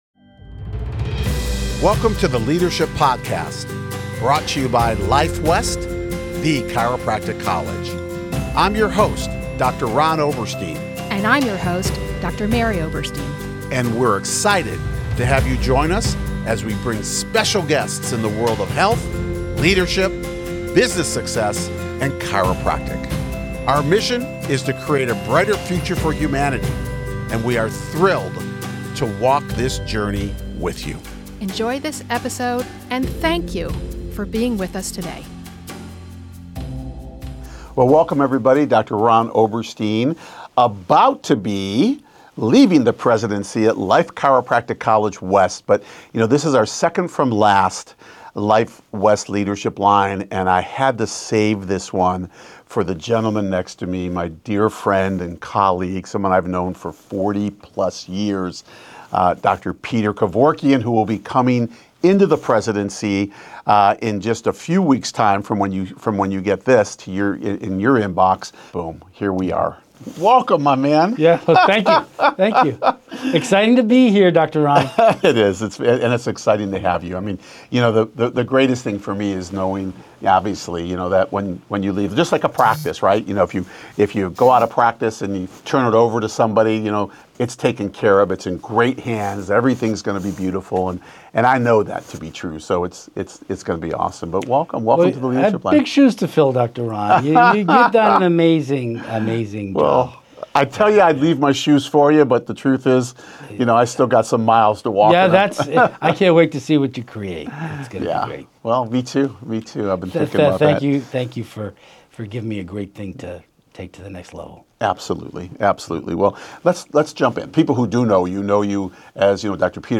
a heartfelt and forward-looking conversation